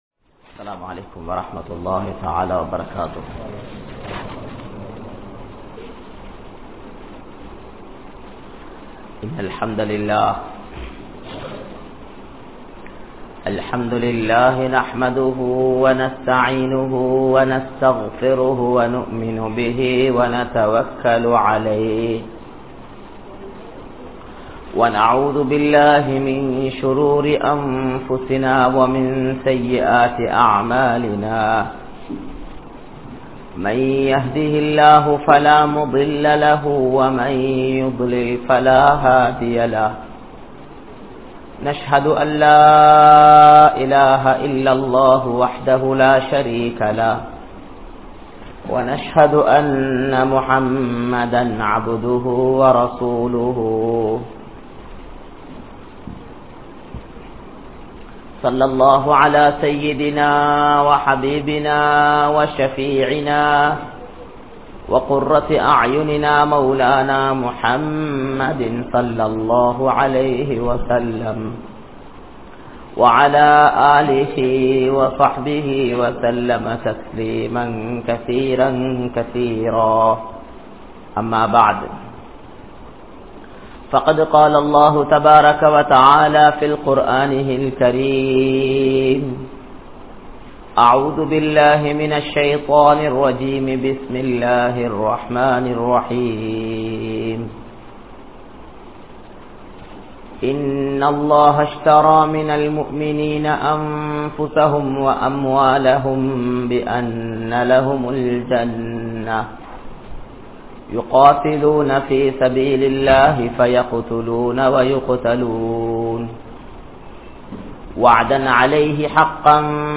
Value of the Da`wah | Audio Bayans | All Ceylon Muslim Youth Community | Addalaichenai